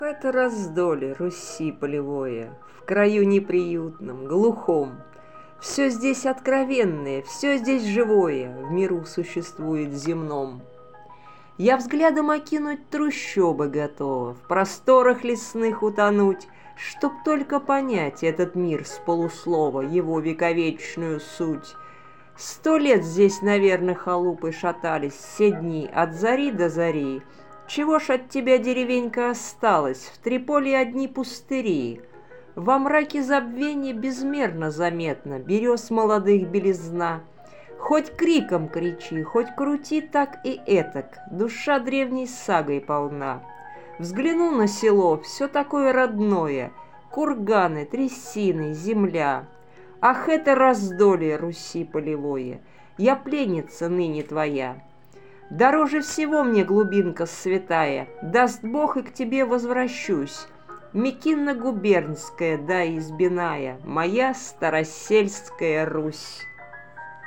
Музыка классики